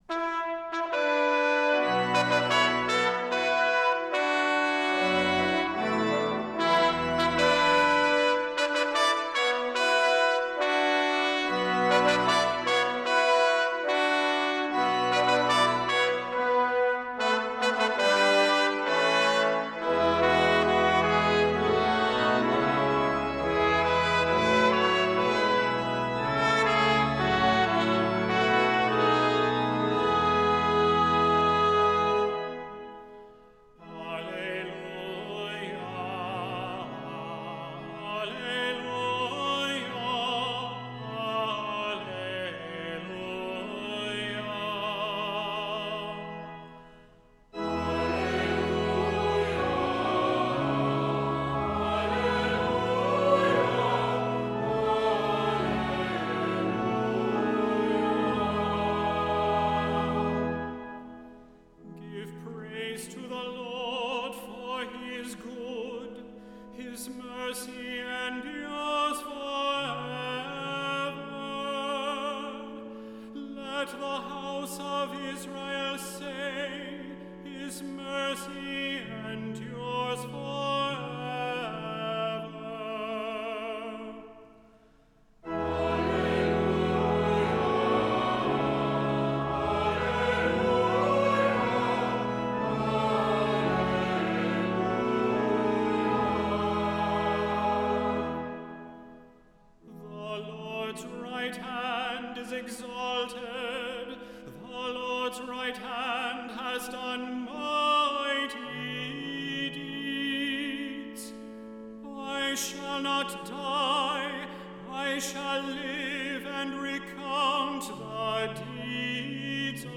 Voicing: "Unison with descant","Cantor","Assembly"